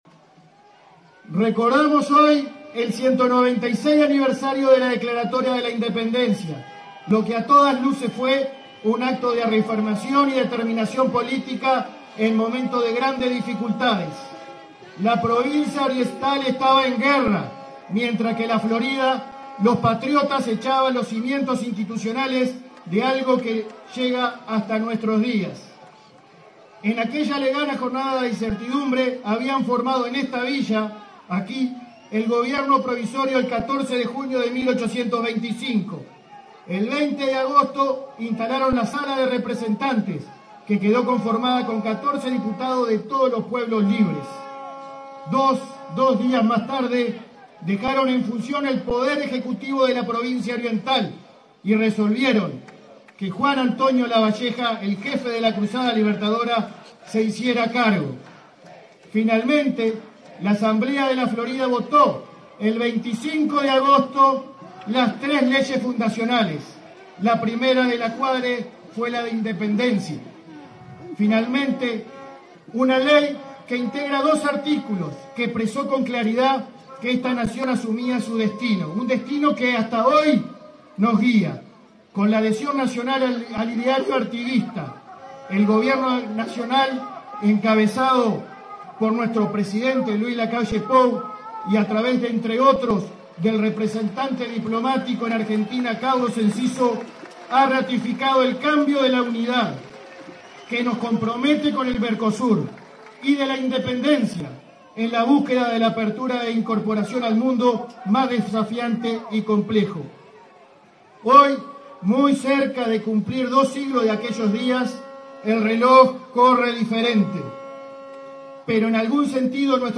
Palabras del intendente Guillermo López y del ministro de Ambiente, Adrián Peña
Palabras del intendente Guillermo López y del ministro de Ambiente, Adrián Peña 24/08/2021 Compartir Facebook X Copiar enlace WhatsApp LinkedIn El intendente de Florida, Guillermo López, y el ministro de Ambiente, Adrián Peña, fueron los oradores del acto conmemorativo del 196.° aniversario de la Declaratoria de la Independencia Nacional, encabezado por el presidente Luis Lacalle Pou.